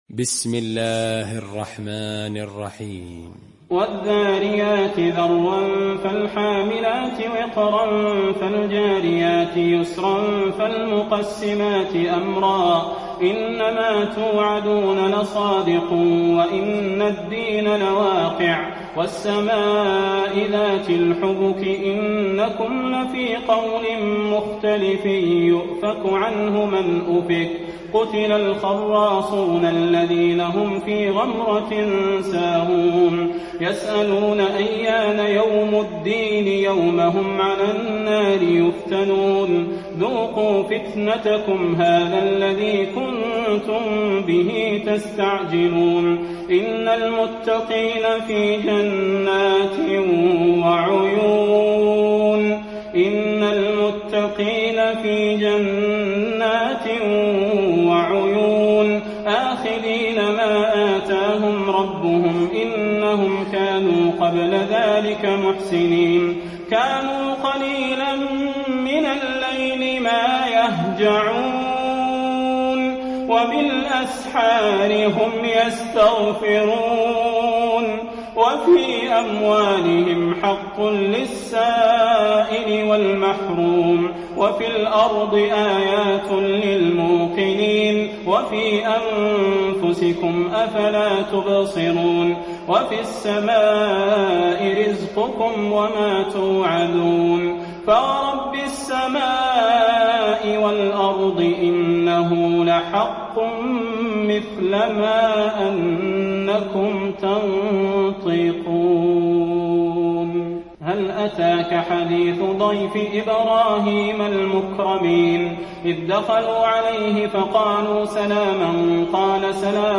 المكان: المسجد النبوي الذاريات